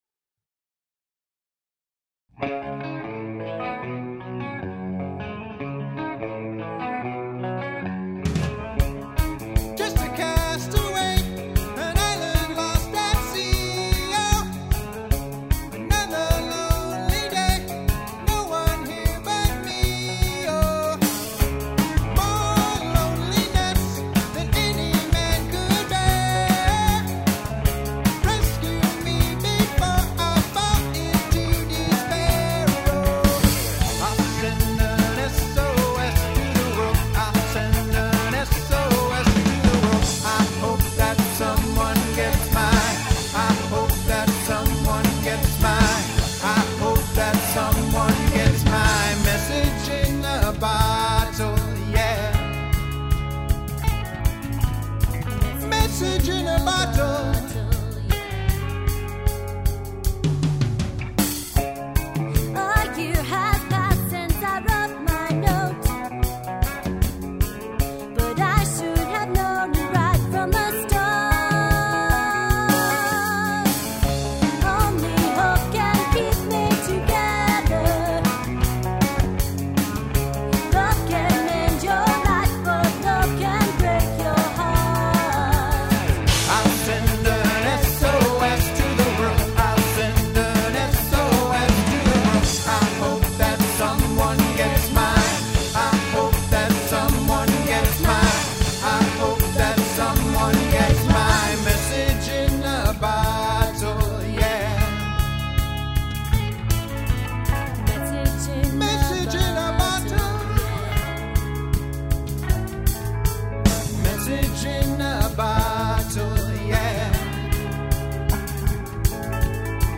Live Music!